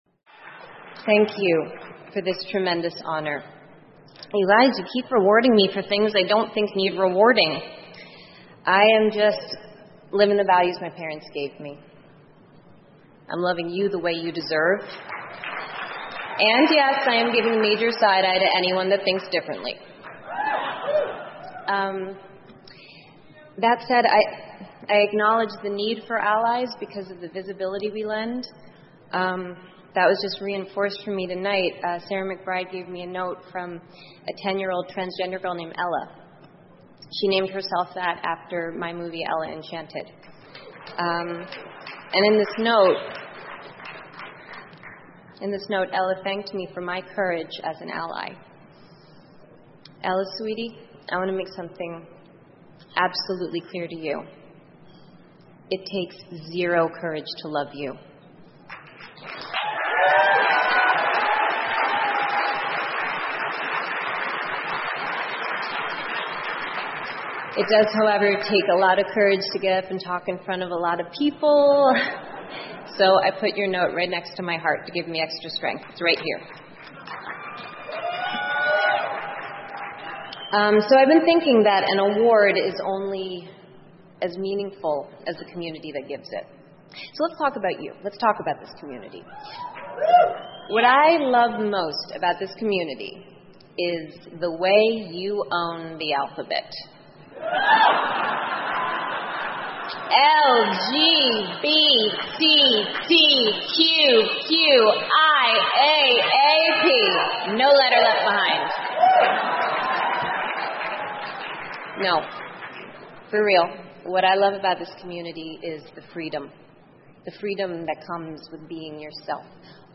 英文演讲录 安妮·海瑟薇：力挺同性恋者(1) 听力文件下载—在线英语听力室